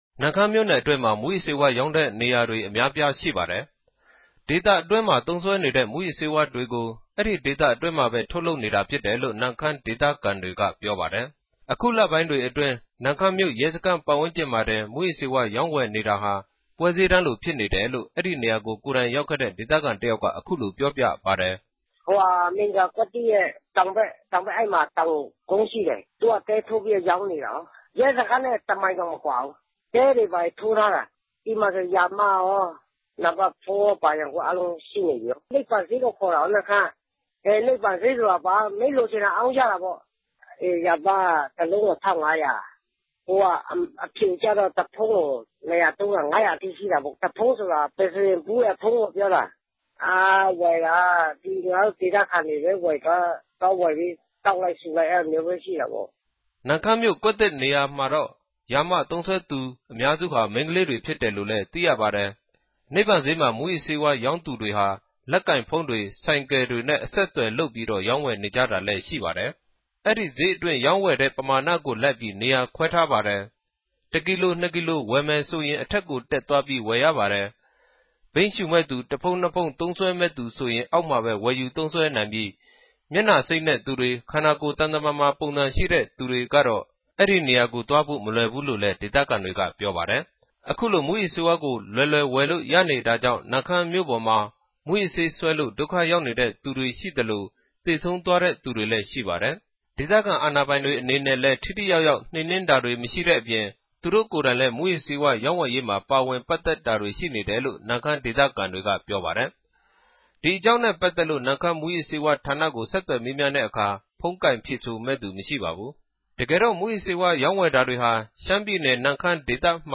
သတင်းပေးပိုႛခဵက်။်